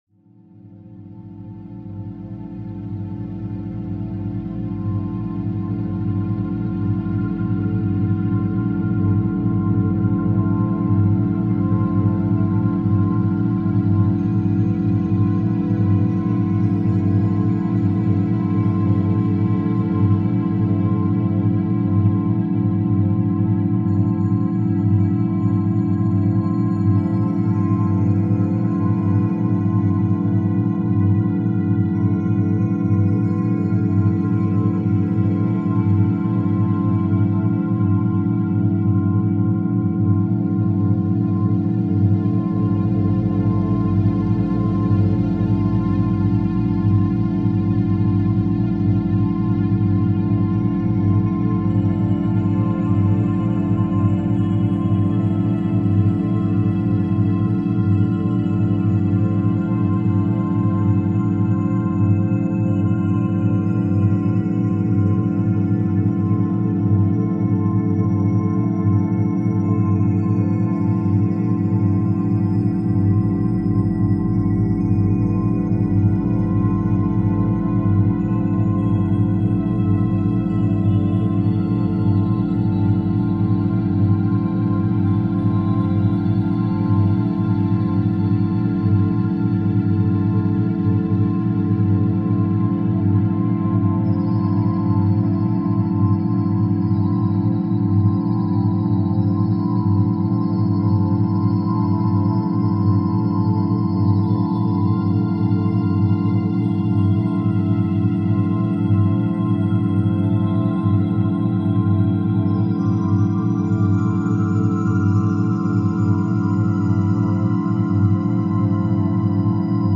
リラクゼーションのためのアルファバイノーラルビート – 11 Hz | 集中、リラクゼーション、創造性